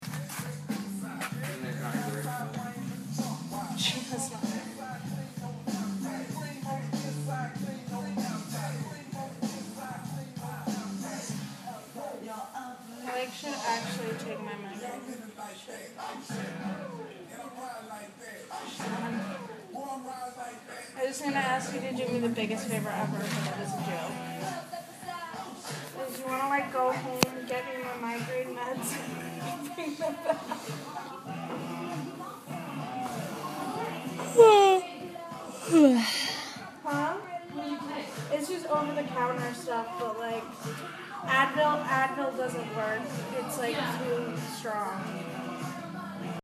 Field Recording – Painting
The field recording of paint call to go along with my sound journal:
2- Someone is coughing, and there are firetrucks in the far distance. I can also hear a clock ticking.